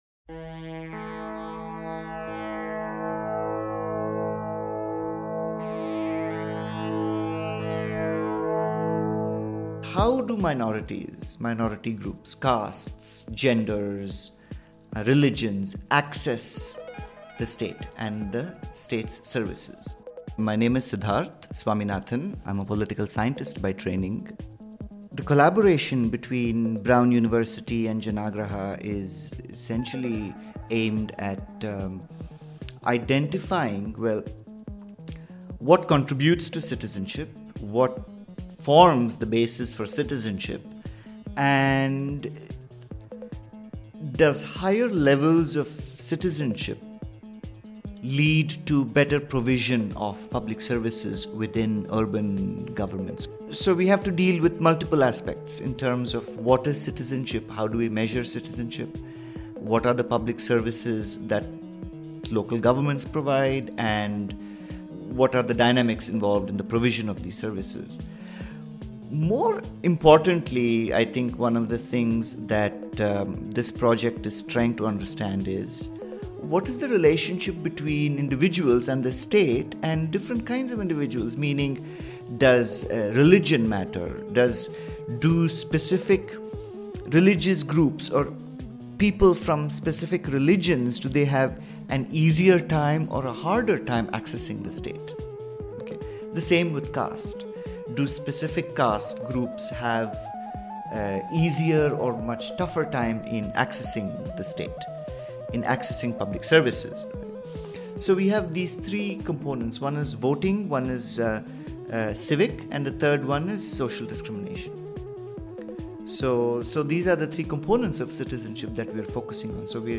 Three scholars speak about measuring citizenship in urban India.